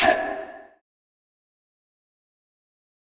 Vox (Keep).wav